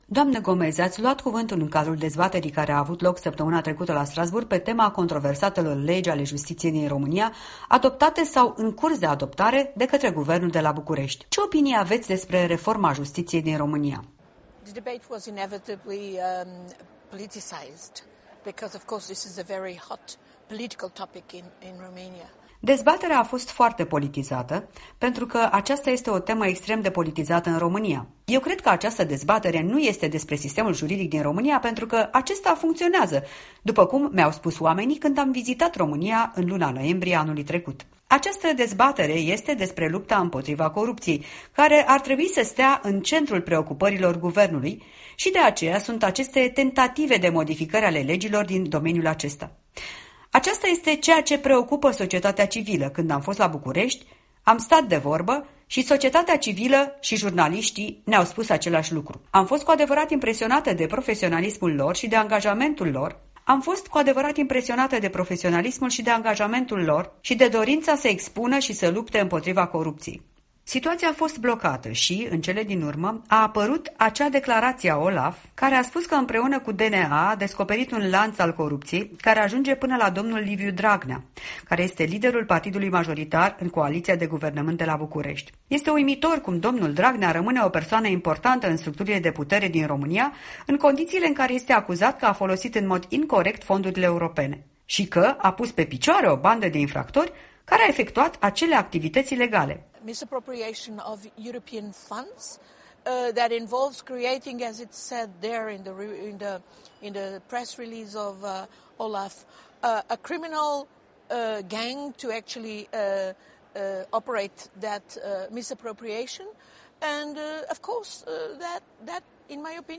Un interviu cu europarlamentara Ana Gomes după dezbaterea din Parlamentul European pe tema modificării legilor justiției în România.
În direct de la Strasbourg cu europarlamentara Ana Gomes